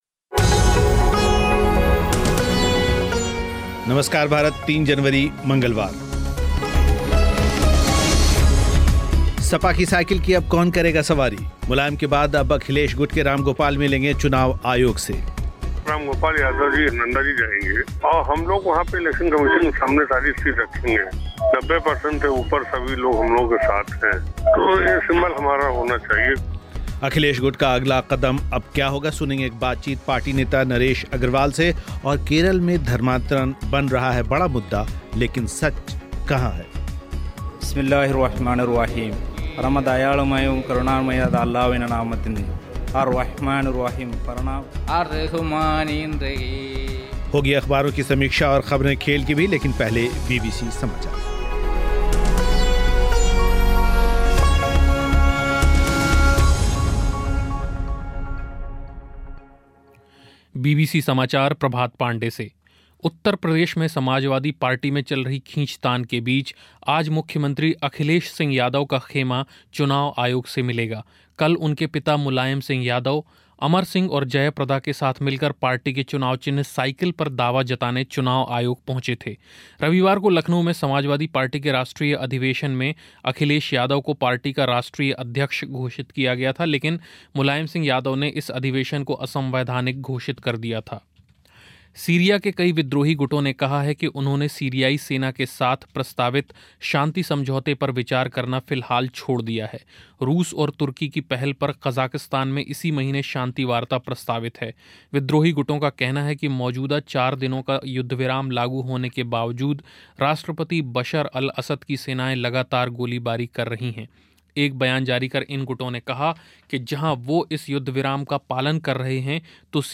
सपा की साइकिल की अब कौन करेगा सवारी? मुलायम के बाद अब अखिलेश गुट के रामगोपाल मिलेंगे चुनाव आयोग सेअखिलेश गुट अपने बचाव में कौन सी बातें कह रहा है, सुनेंगें एक बातचीत पार्टी नेता नरेश अग्रवाल से और, केरल में धर्मांतरण बन रहा है बड़ा मुद्दा, लेकिन सच कहां है साथ ही अख़बारों की समीक्षा और ख़बरें खेल की और बीबीसी समाचार